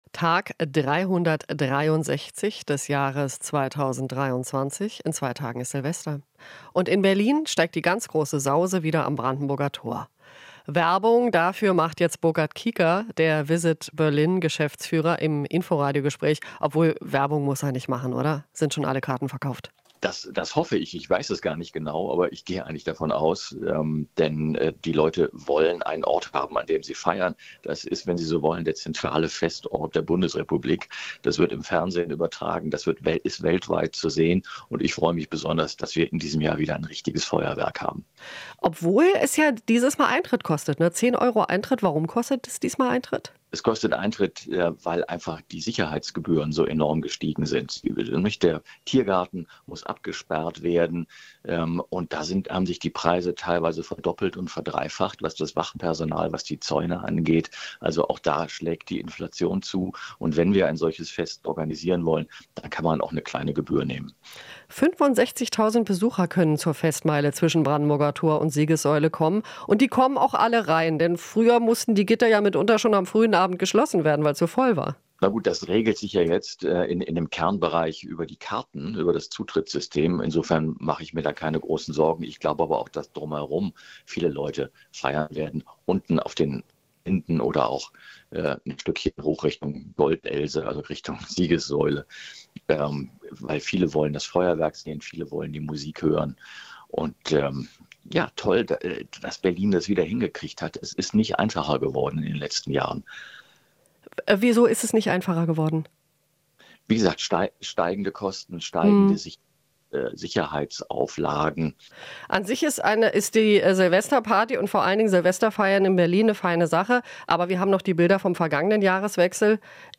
Interview - Visitberlin: Silvester-Randale schreckt Touristen nicht ab